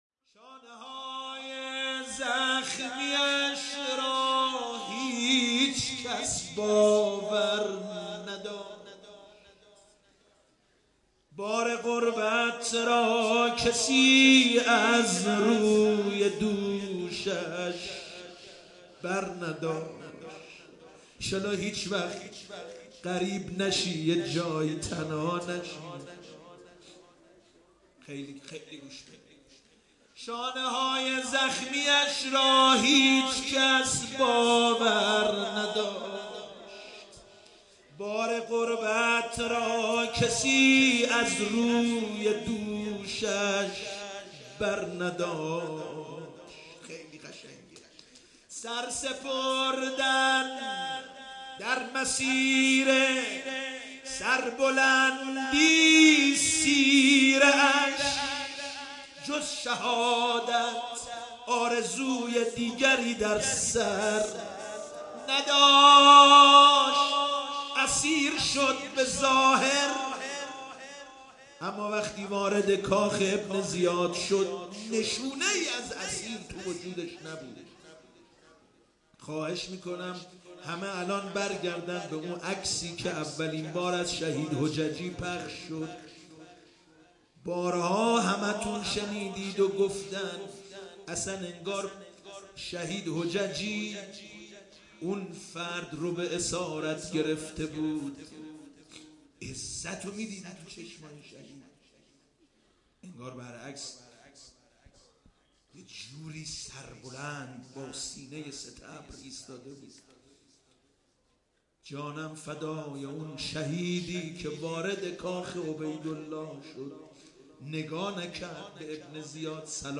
نوحه جديد